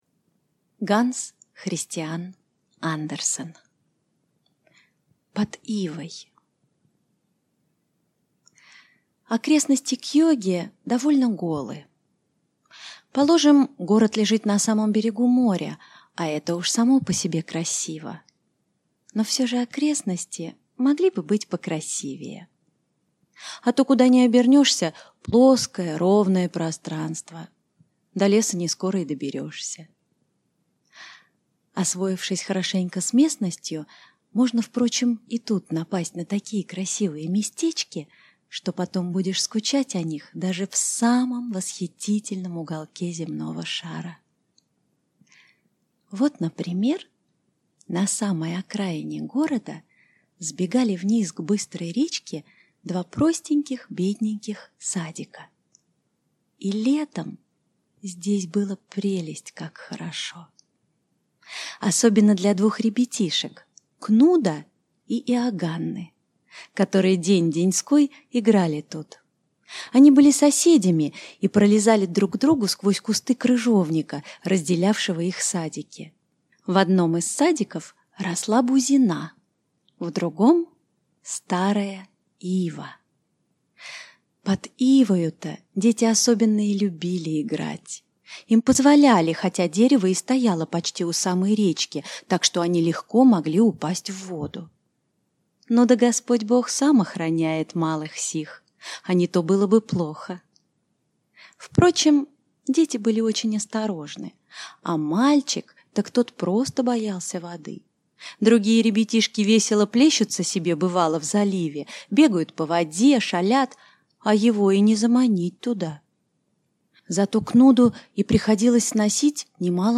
Аудиокнига Под ивой | Библиотека аудиокниг